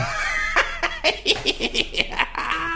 evil-laugh-2.ogg